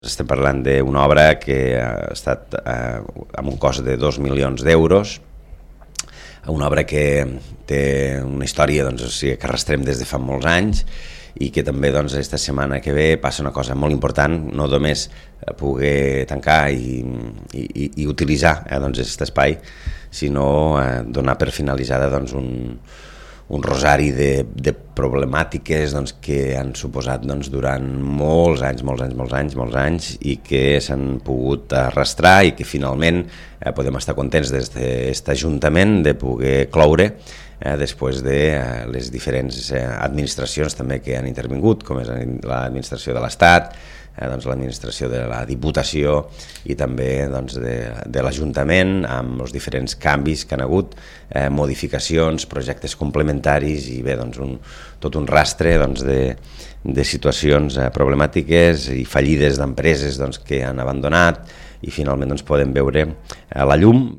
Jordi Gaseni és l’alcalde de l’Ametlla de Mar: